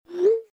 Звук отправки исходящего сообщения